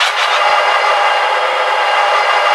rr3-assets/files/.depot/audio/sfx/transmission_whine/tw_onverylow.wav